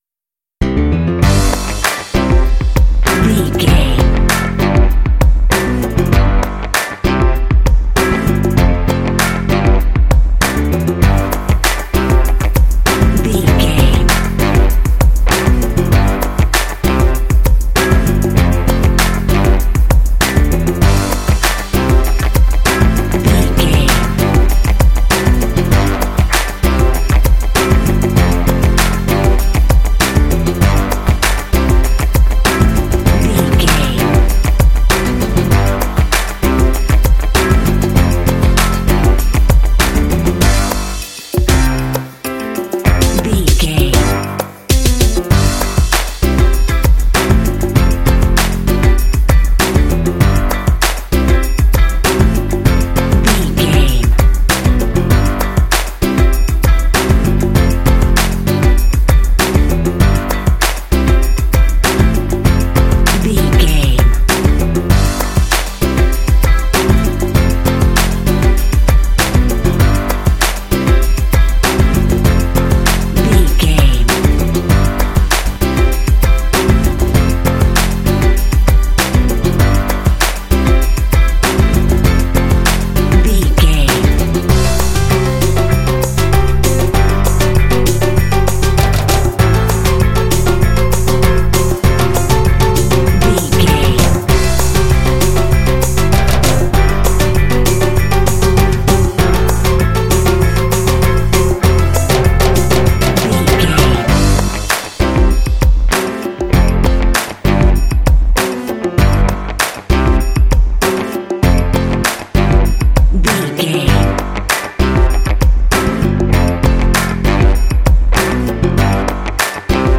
This cute hip hop track is great for kids and family games.
Uplifting
Ionian/Major
bright
happy
bouncy
piano
bass guitar
electric organ
percussion
strings
drums
Funk